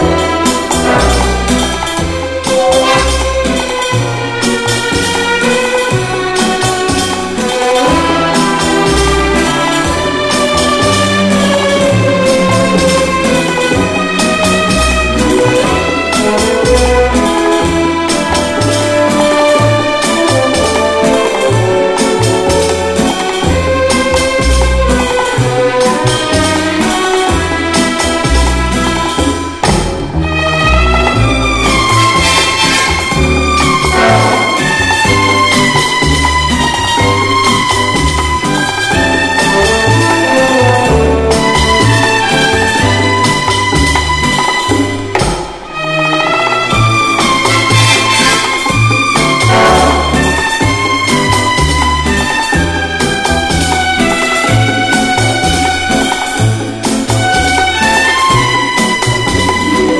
ROCK / 60'S / BRITISH BEAT
アップテンポなリズムから終わりと見せかけてツイスト・リズムに持っていく辺り
ブルース・ハープが大暴れの